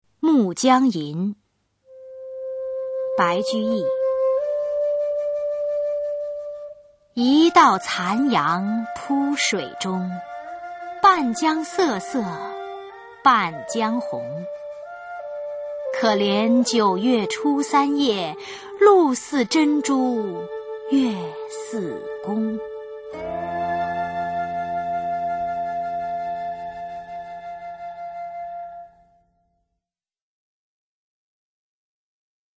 [隋唐诗词诵读]白居易-暮江吟 唐诗诵读